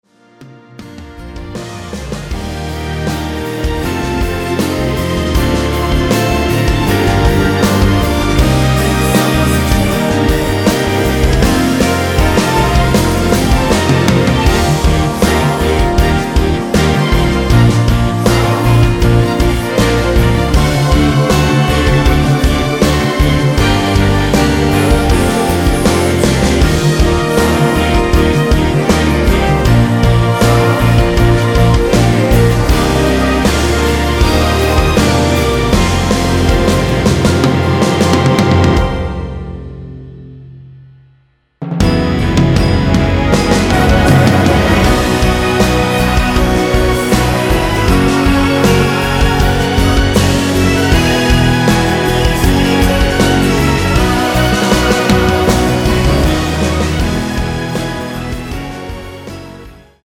원키에서(-1)내린 코러스 포함된 MR입니다.
Bb
앞부분30초, 뒷부분30초씩 편집해서 올려 드리고 있습니다.
중간에 음이 끈어지고 다시 나오는 이유는